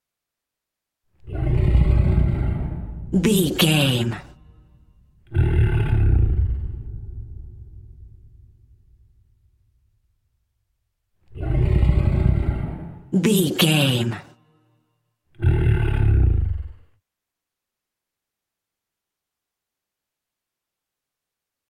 Monster snarl short distant dinosaur with without rvrb
Sound Effects
ominous
disturbing
eerie